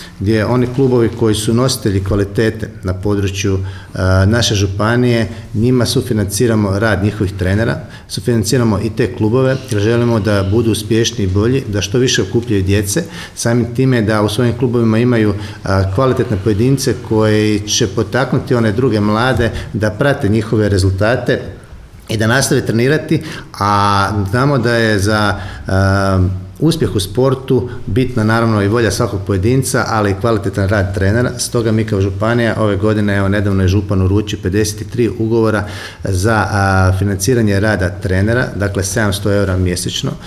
Jurić je, u ovoj prigodi, podsjetio kako je Županija osnovala i Centar izvrsnosti u sportu